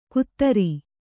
♪ kuttari